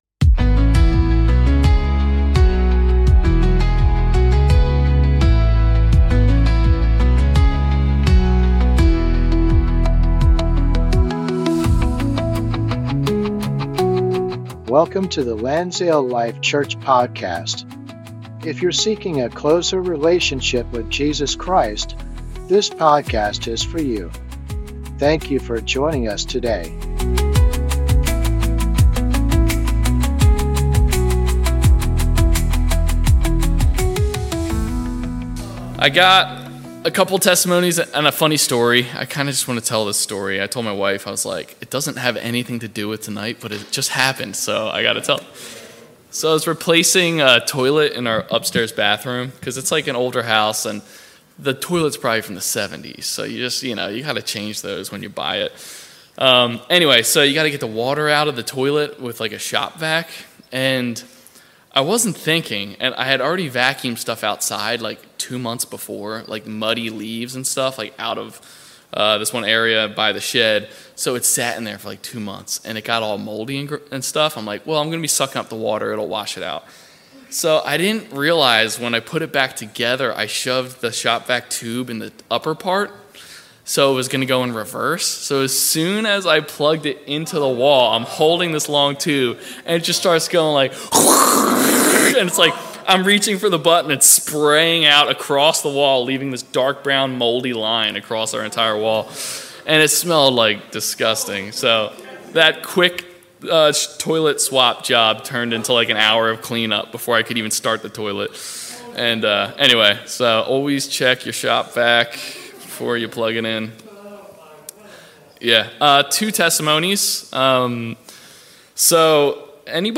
An ongoing bible study on the book of Numbers.